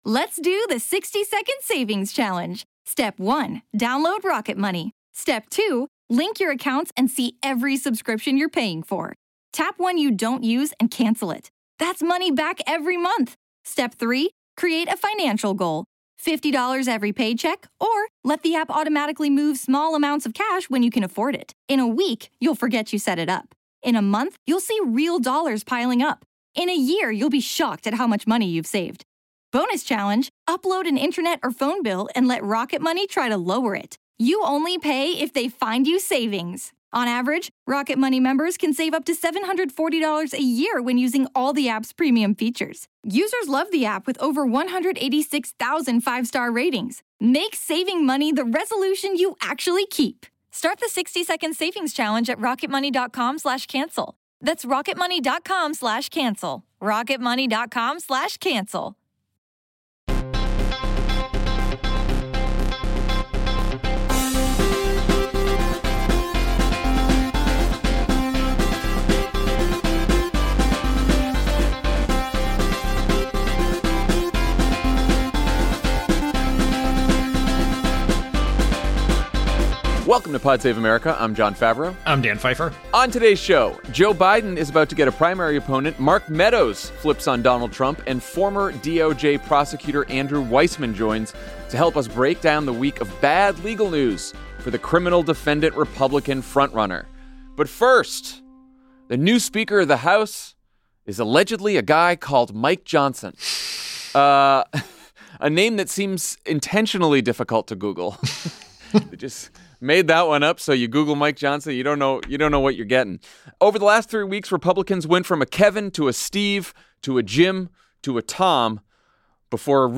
Former Mueller prosecutor Andrew Weissmann joins the show to break down Donald Trump's week of bad legal news. And then: President Biden is about to face a new primary opponent.